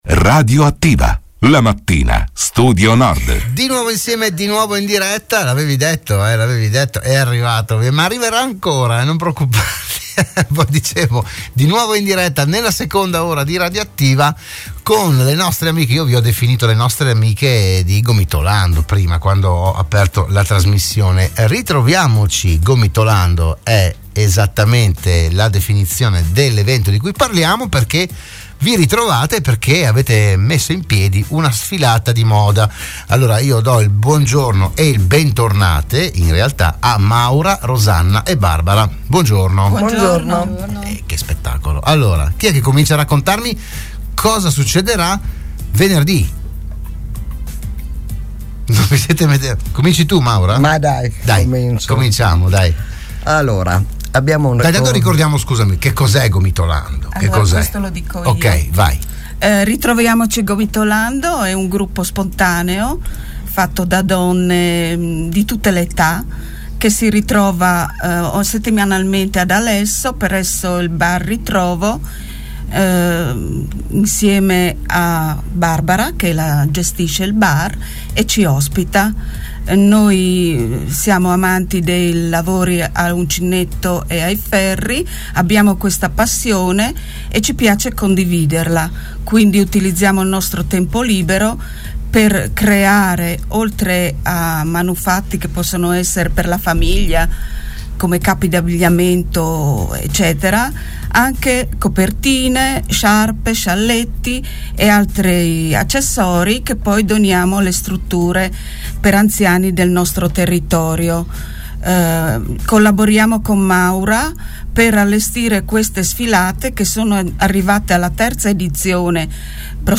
Se n'è parlato alla trasmissione "Radioattiva" di Radio Studio Nord